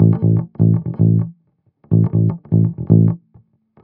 11 Bass Loop A.wav